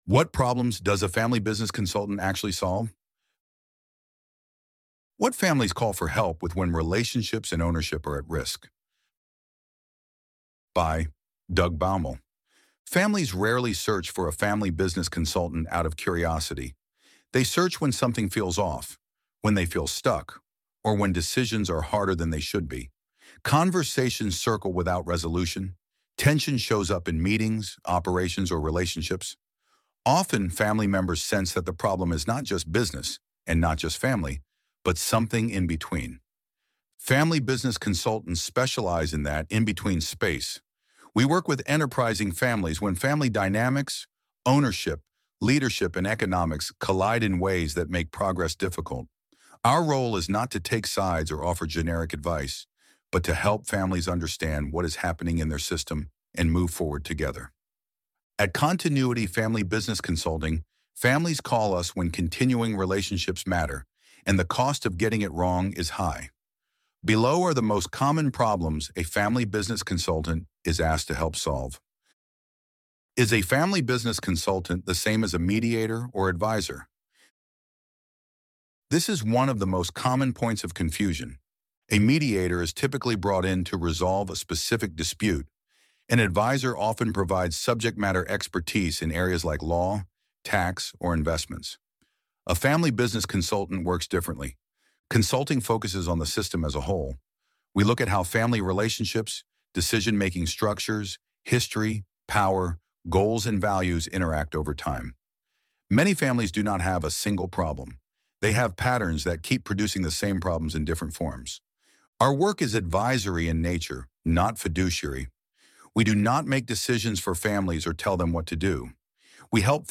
6-7 mins + FAQs Loading the Elevenlabs Text to Speech AudioNative Player...